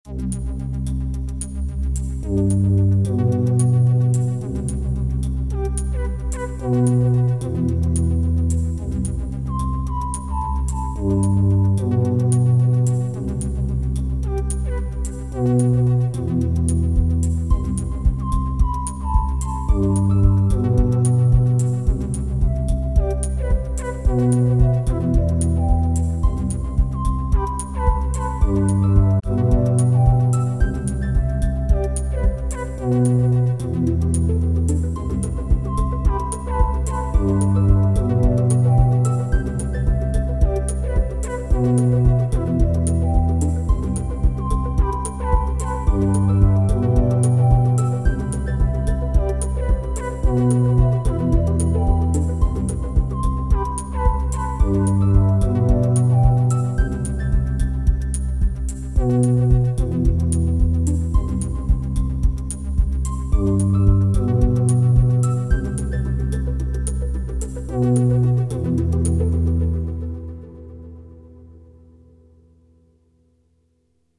After doing all the percussion and dark drone stuff I wanted to make something a bit more softer and beautiful :heartpulse: Turns out, SY TOY can do that as well :stuck_out_tongue: (also, spot the micro fade because Overbridge glitched and I couldn’t be bothered recording it again…)
Perc giving dynamic and other sounds are perfect too.